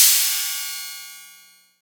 • Crash Cymbal Single Hit C Key 05.wav
Royality free crash cymbal audio clip tuned to the C note.
crash-cymbal-single-hit-c-key-05-sT9.wav